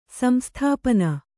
♪ samsthāpana